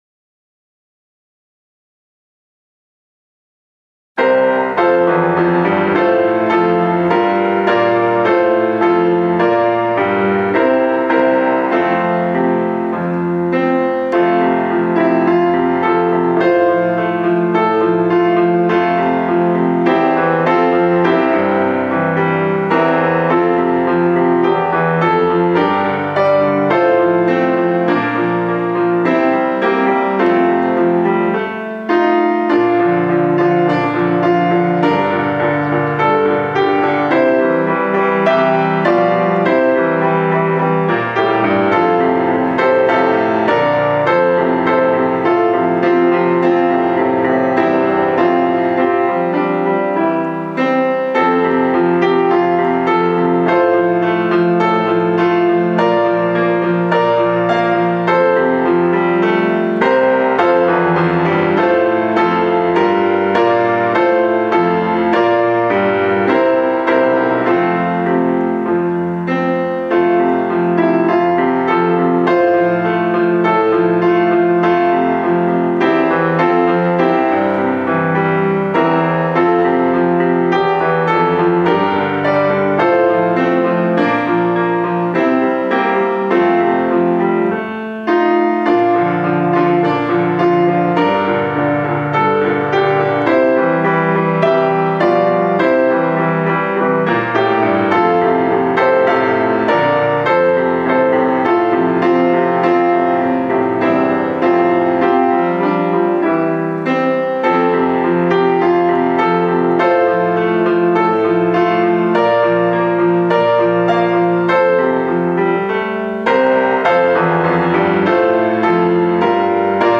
生出讃歌　ピアノ伴奏